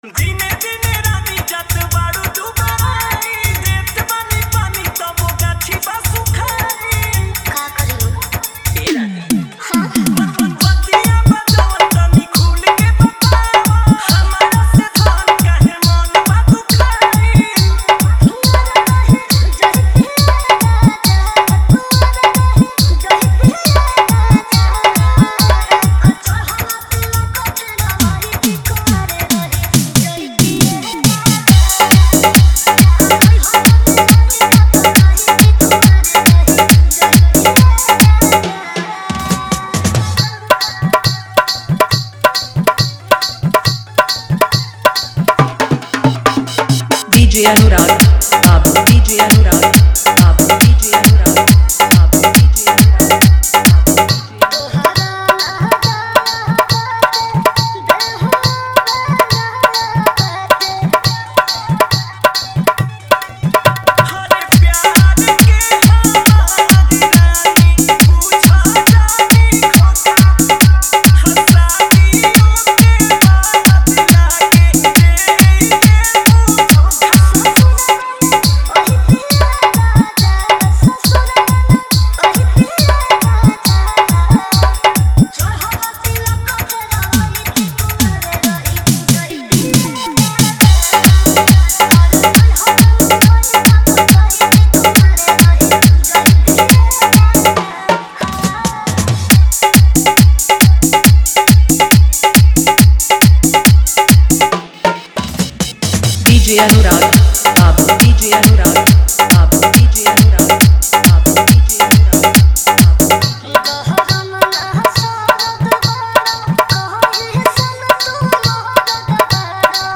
hard dholki mix